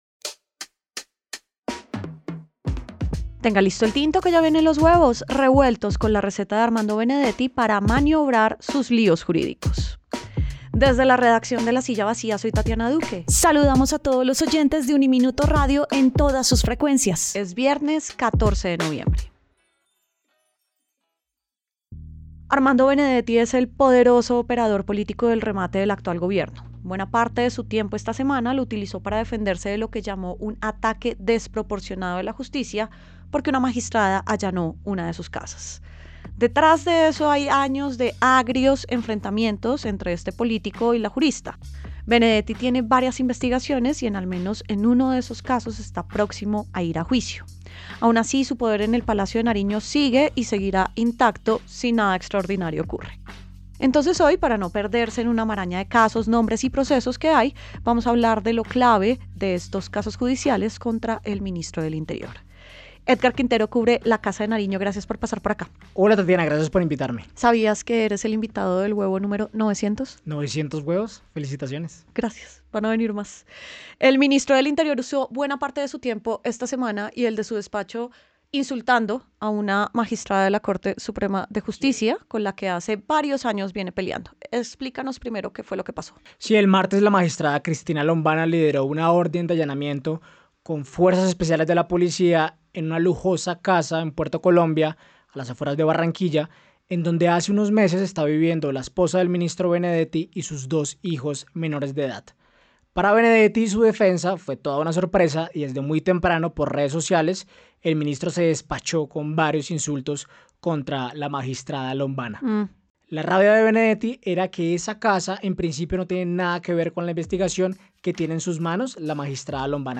El Árbol Rojo: entrevista